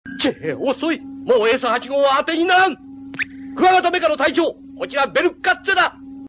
yells at his men for incompetence or calls them